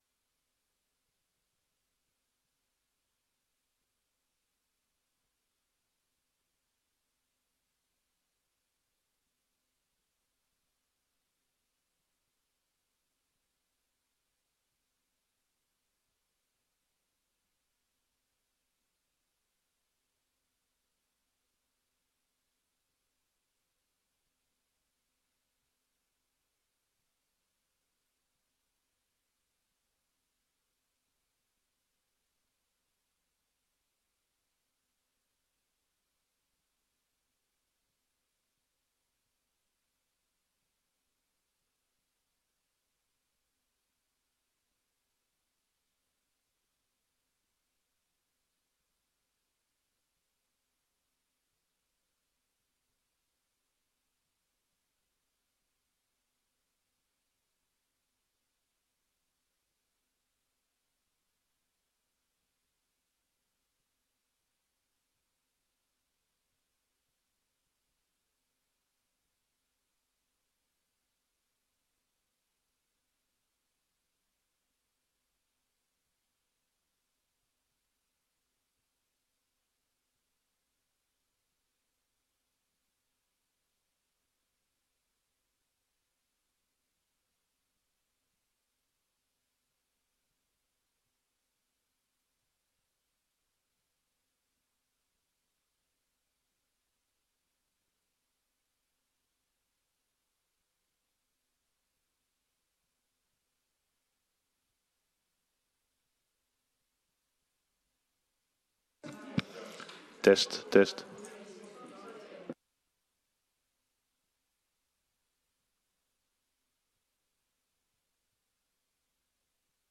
Download de volledige audio van deze vergadering
Extra raadsvergadering in verband met de zienswijze van de gemeenteraad West Betuwe op de voorgenomen besluiten van het Ministerie van Defensie over de radartoren Herwijnen